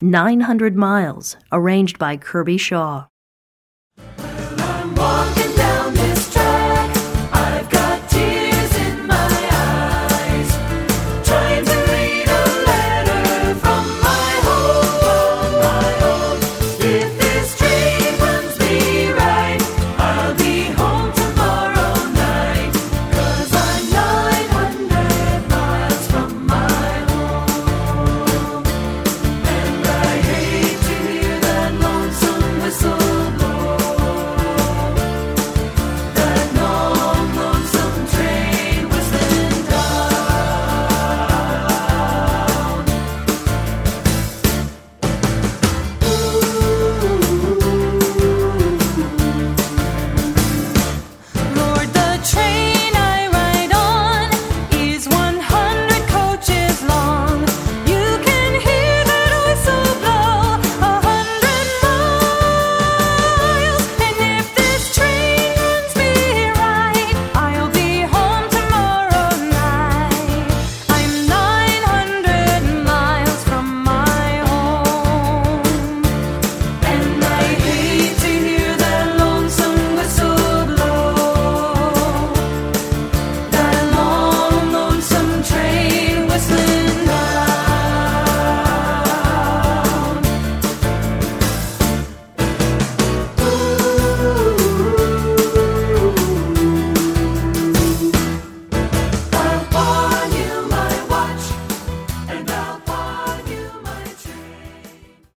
Composer: Traditional American
Voicing: SAB